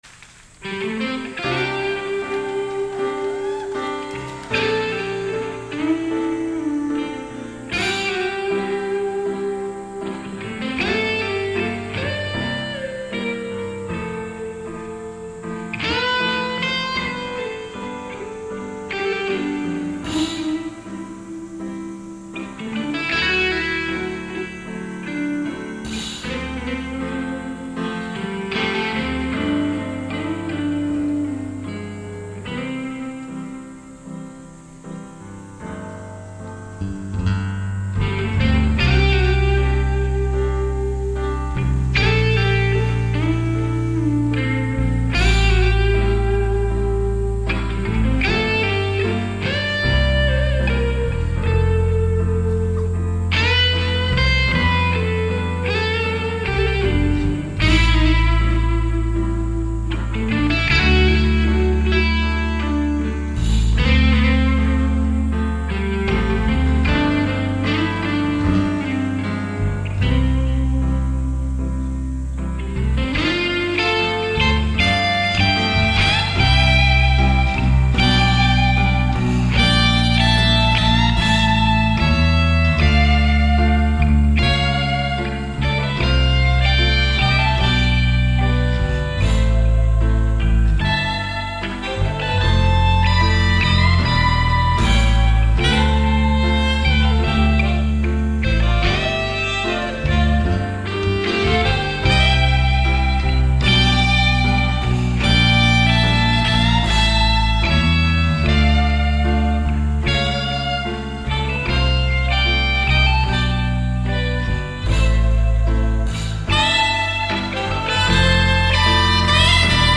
20주년 공연